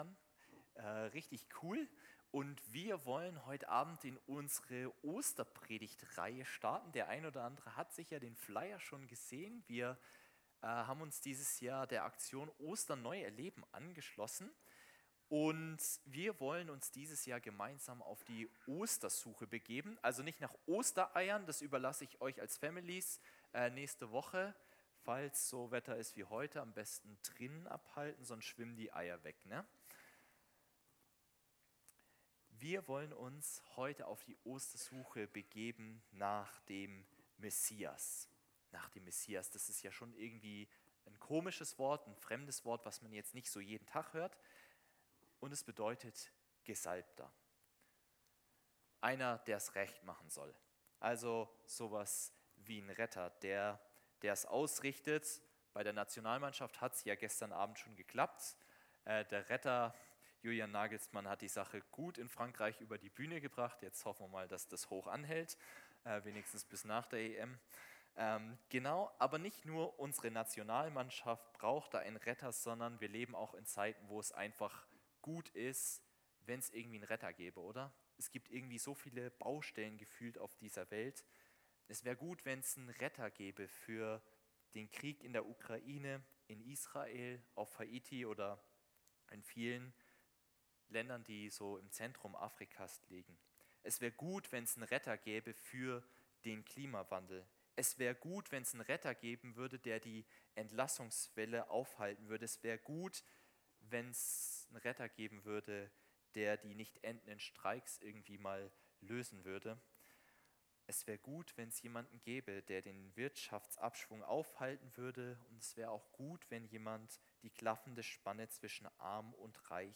Gottesdienst am 24.03.2024 ~ Liebenzeller Gemeinschaft Schopfloch Podcast